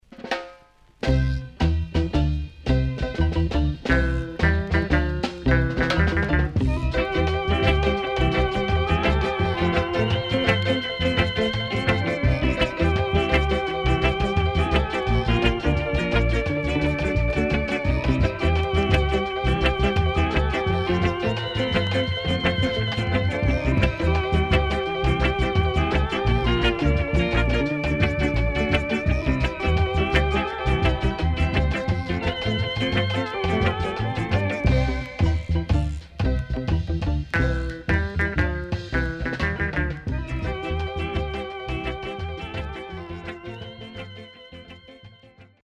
Nice Vocal & Inst.Good Condition
SIDE A:少しノイズ入りますが良好です。